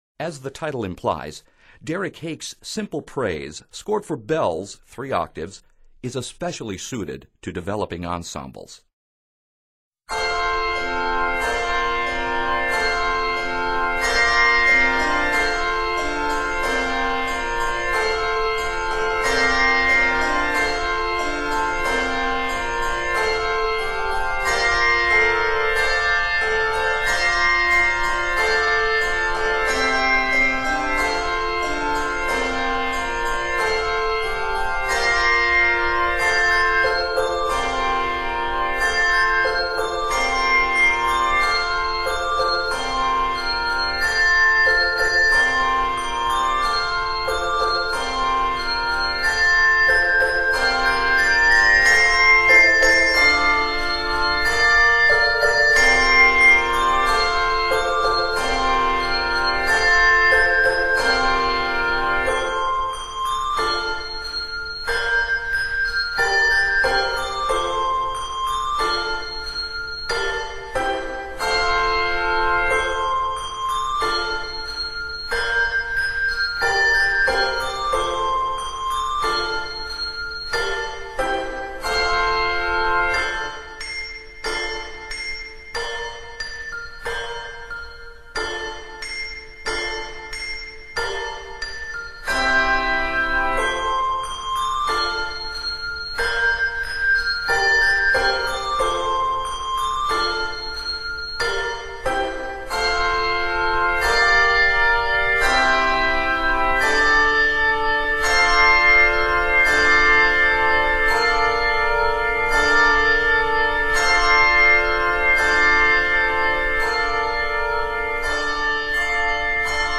Three early-American shaped-note hymn tunes